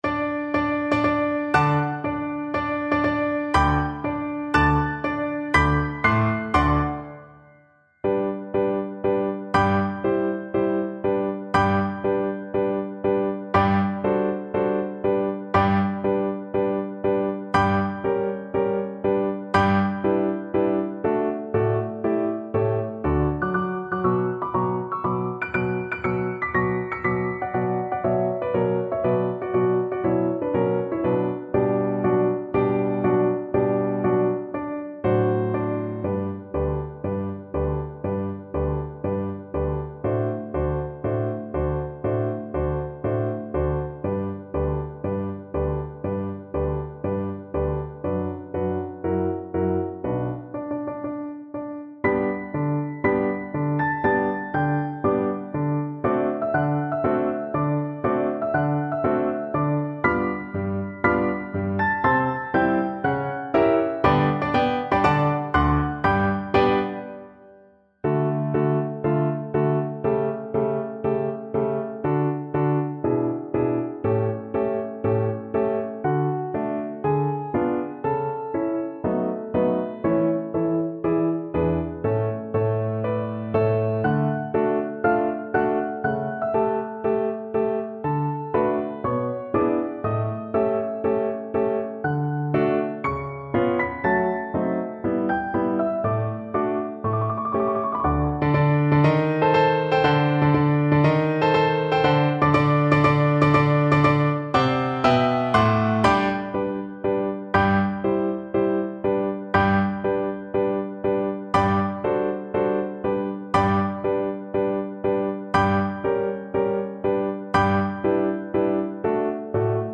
4/4 (View more 4/4 Music)
Allegro marziale (View more music marked Allegro)
Classical (View more Classical Viola Music)